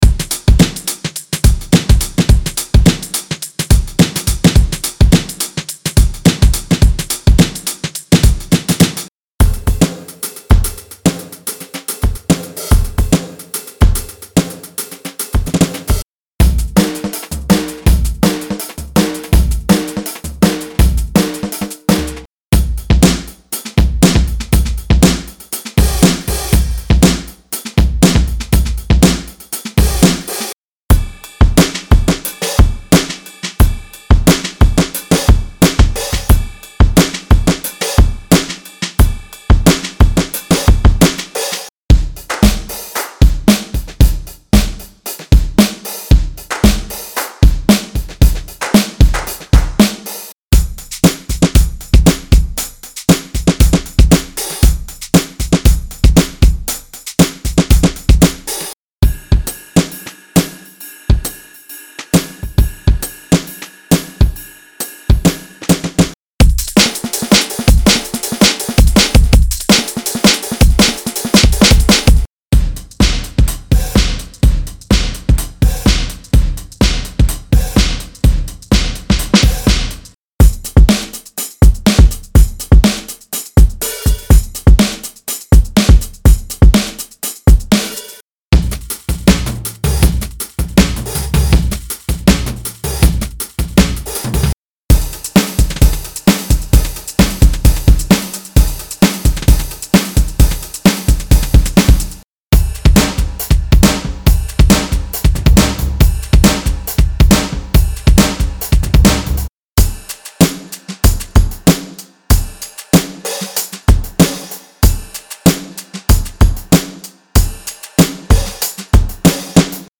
Knowing this, we hired a Grammy-winning drummer to go inside the studio and record 790 acoustic drum samples for you in this pack.
Unison-Acoustic-Drum-Pack-Demo.mp3